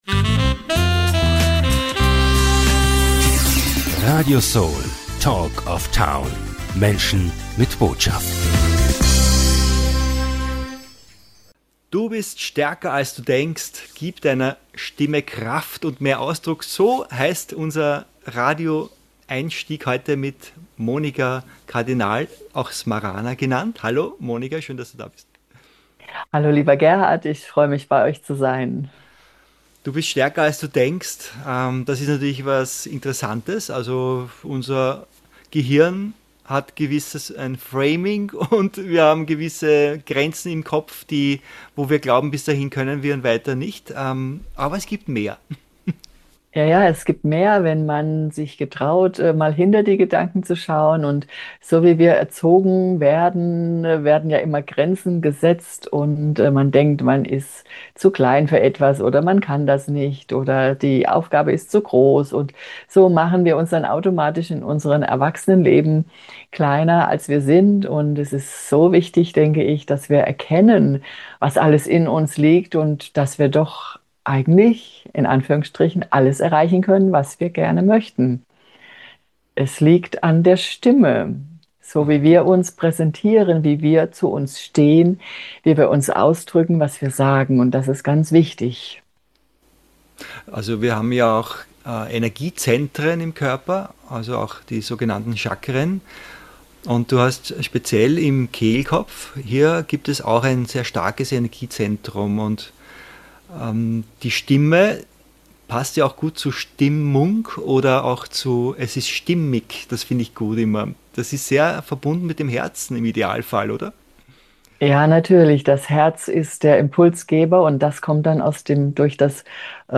In diesem Interview zeigt sie, wie man der eigenen Stimme mehr Kraft und Ausdruck verleiht.